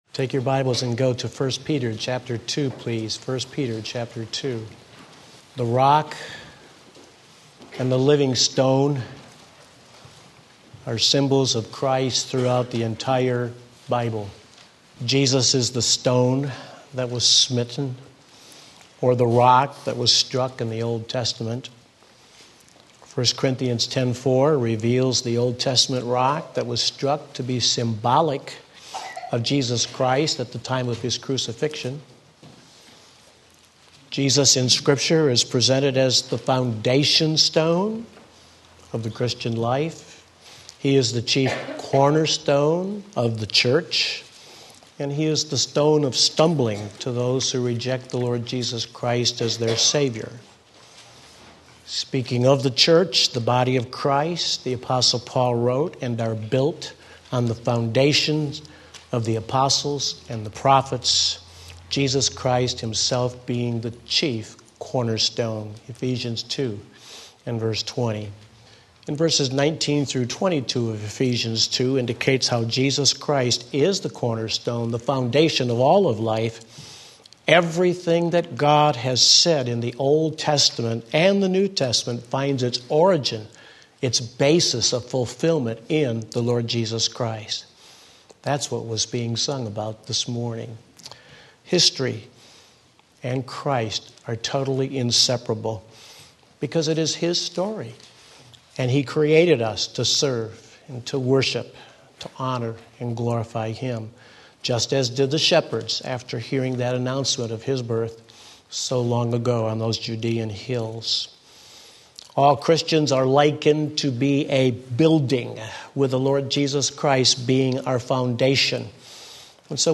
Sermon Link
The Real Meaning of Christmas 1 Peter 2:4-9 Sunday Morning Service